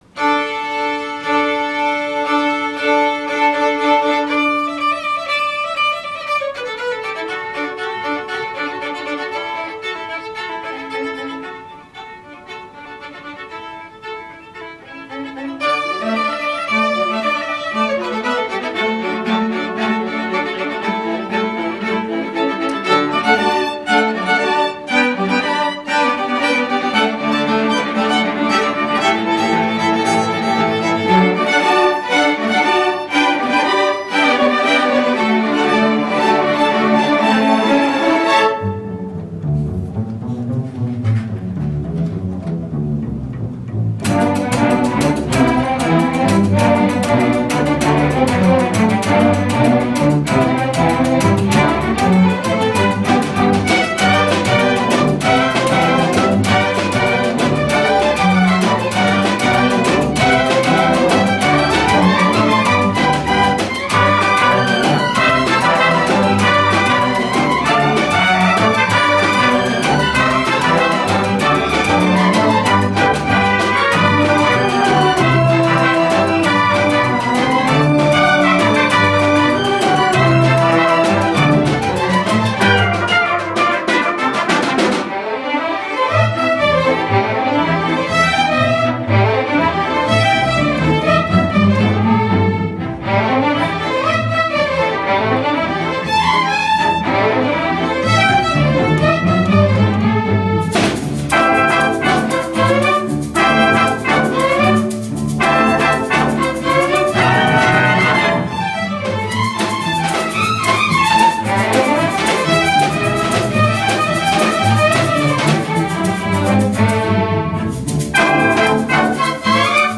Theme: Americana, Halloween
Ensemble: Full Orchestra